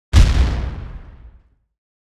grenade.wav